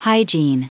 Prononciation du mot hygiene en anglais (fichier audio)
Prononciation du mot : hygiene